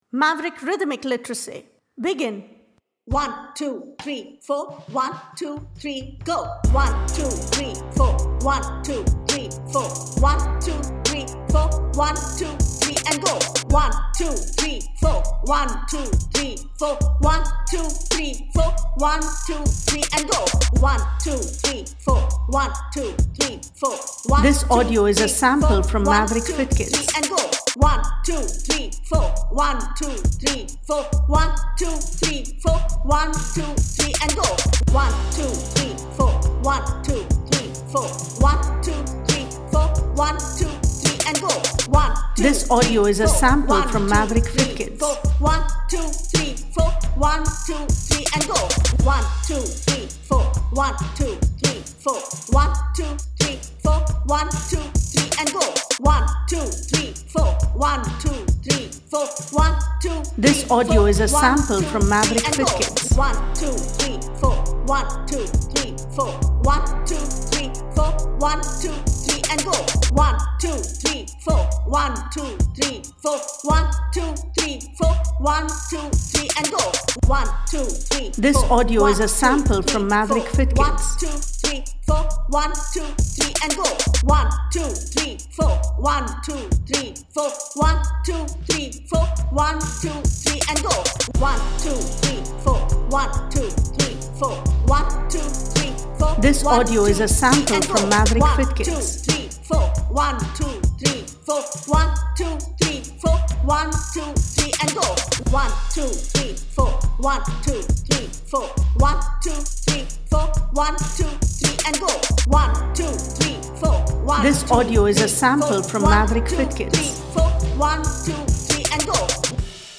Double speed:
RL68-double-sample.mp3